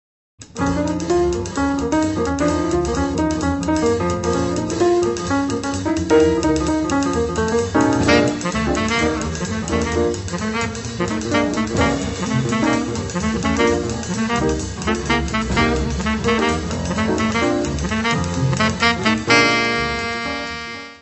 Music Category/Genre:  Jazz / Blues